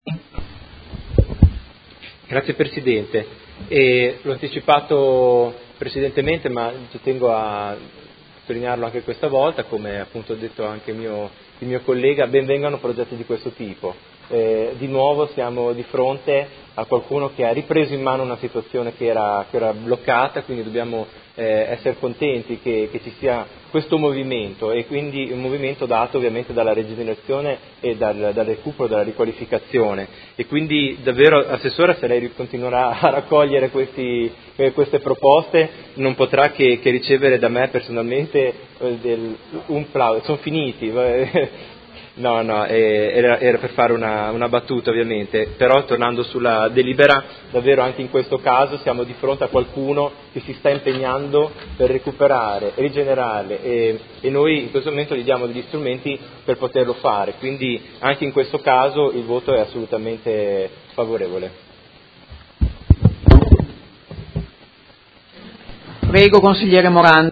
Seduta del 19/07/2018 Dibattito.